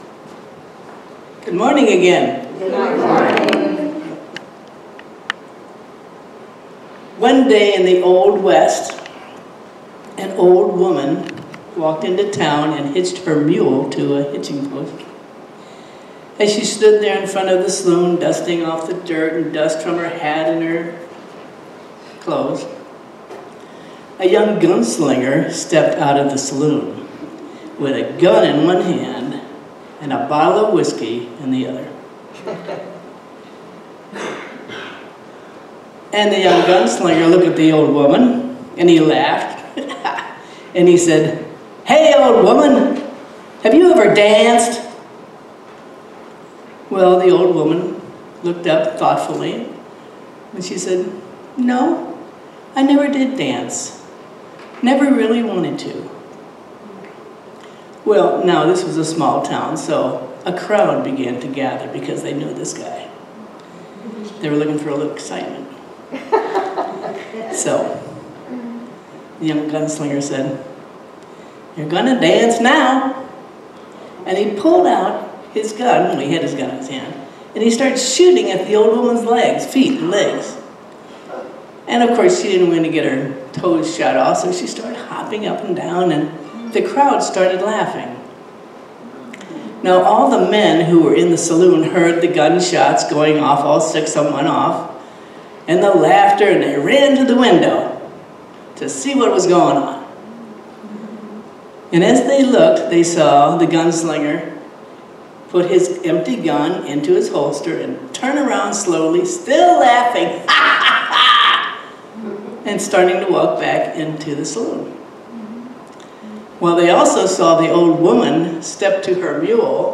Series: Sermons 2025